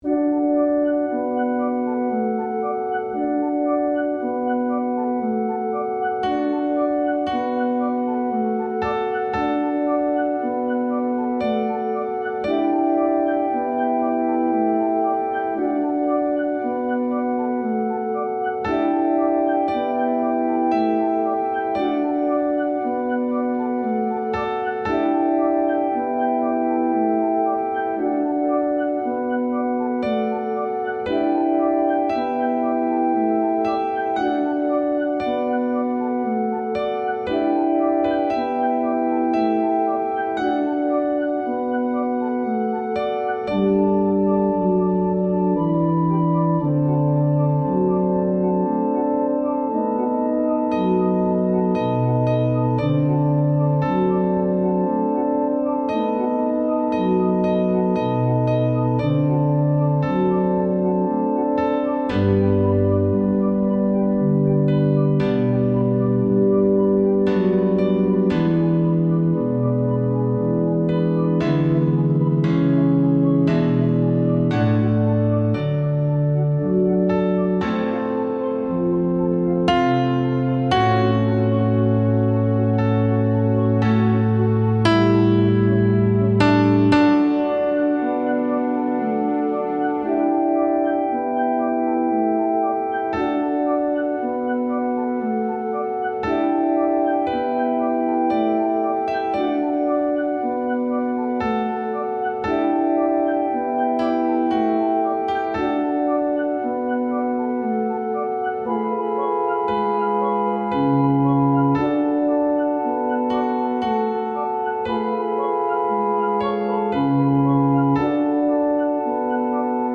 Contralto
Mp3 Música
Contralto-In-Paradisum-MUSICA-Mp3.mp3